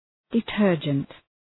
Προφορά
{dı’tɜ:rdʒənt}